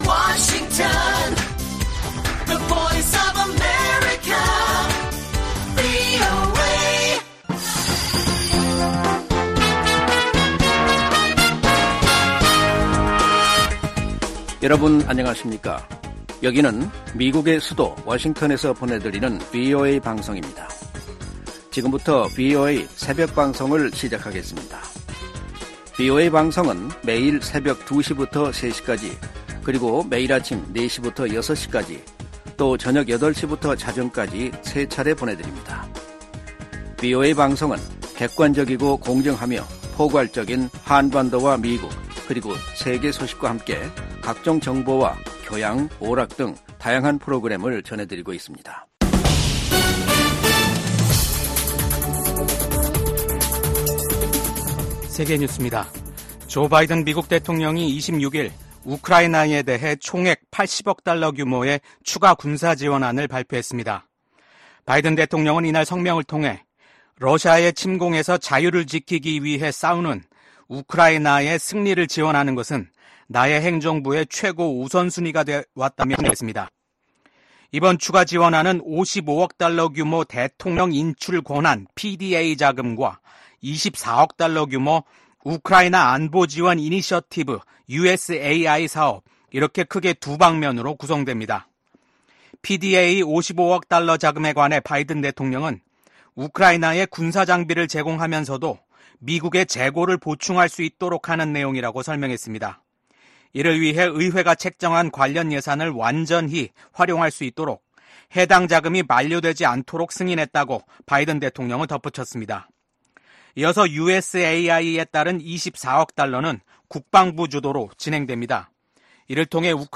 VOA 한국어 '출발 뉴스 쇼', 2024년 9월 27일 방송입니다. 미한일 3국이 외교장관 회의를 개최하고 ‘정치적 전환기’ 속 변함 없는 공조 의지를 확인했습니다. 미국 정부는 북한의 7차 핵실험이 정치적 결정만 남은 것으로 평가한다고 밝혔습니다.